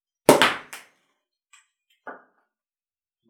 • champagne pop cork ricoche.wav
Recorded in a wooden gazebo with a Tascam DR 40, while opening an old bottle of champagne, smashing against the wooden walls.
champagne_pop_cork_hia.wav